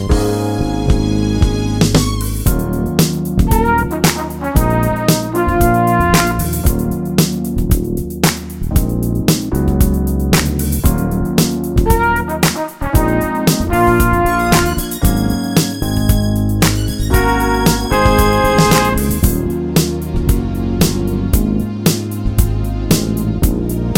No Guitar or Backing Vocals Pop (1980s) 3:44 Buy £1.50